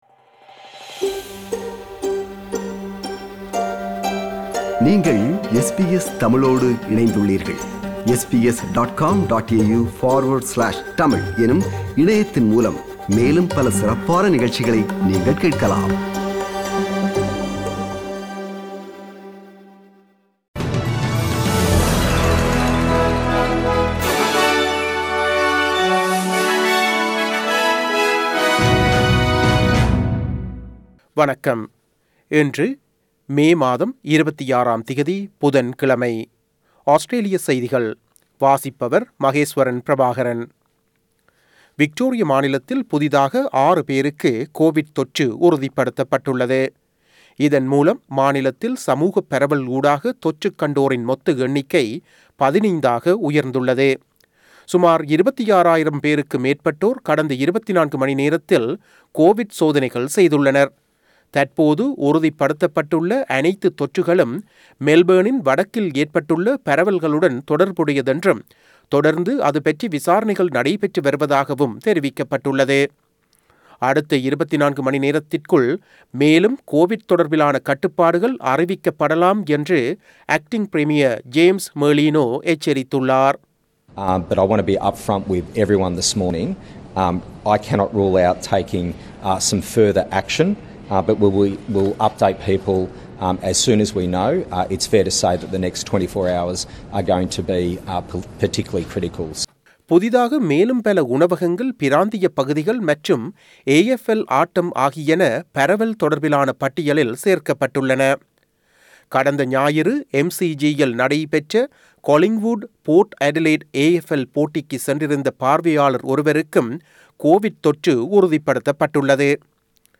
Australian news bulletin for Wednesday 26 May 2021.